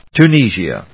Tu・ni・si・a /t(j)uːníːʒ(i)ətjuːníziə/